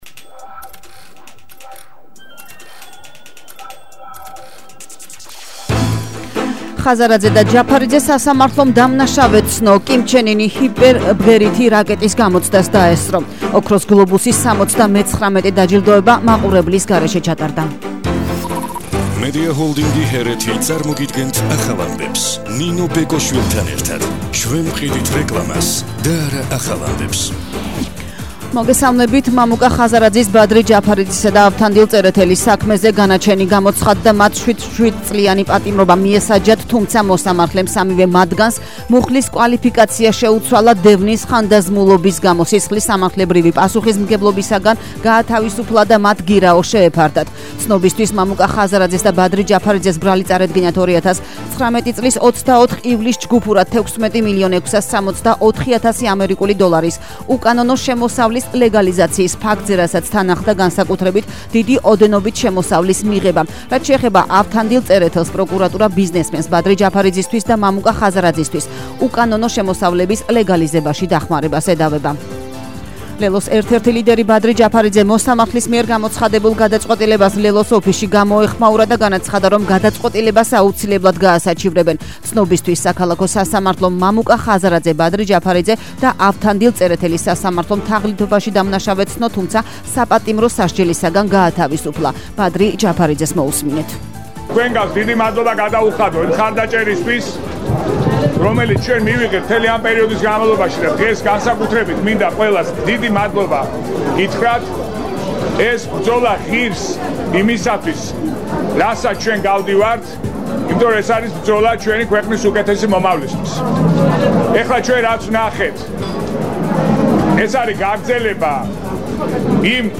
ახალი ამბები 12:00 საათზე – 12/01/22 – HeretiFM